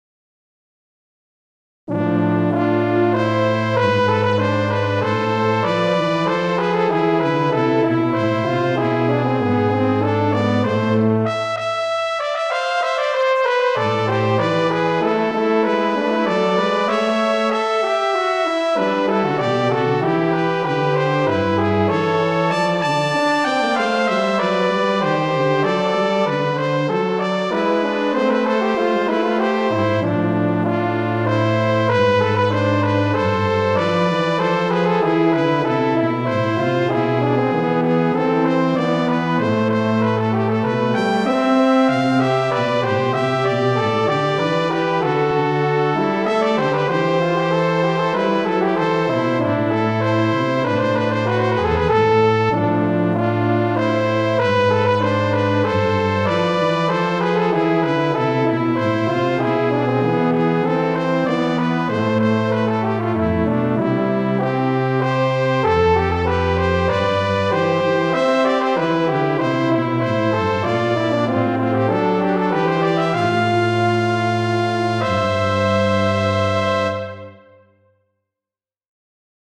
comment 三重奏編成のための習作です。
金管楽器三重奏のイメージです。a minor。